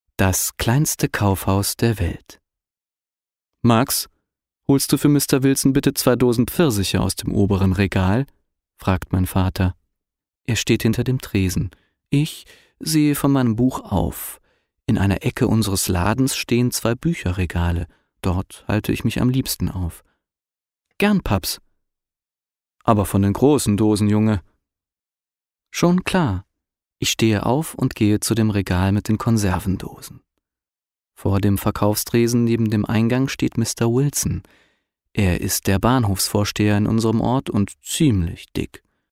Meine Stimme klingt warm, präzise, jung, klar und facettenreich.
Sprechprobe: Sonstiges (Muttersprache):
Young age warm sounding, friendly and fresh voice.